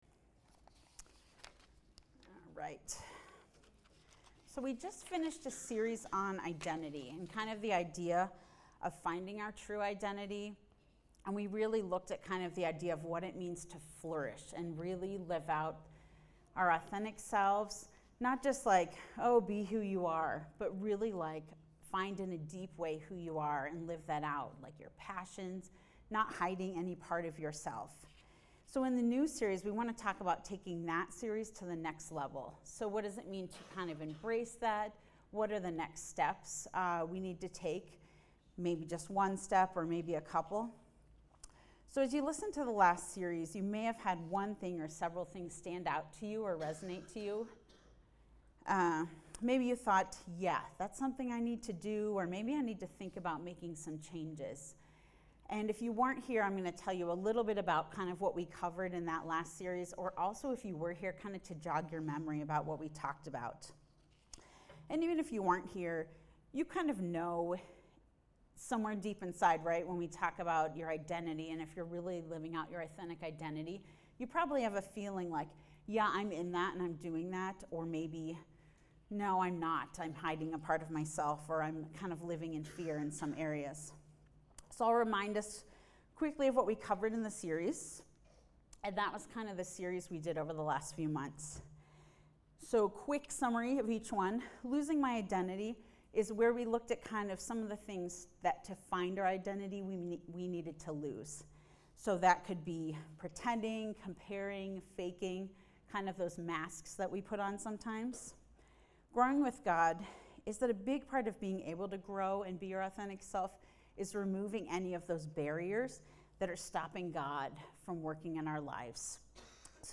Watch or listen to recent Sunday messages and series from The Journey Church in Westminster, CO. New sermons posted weekly with video and notes.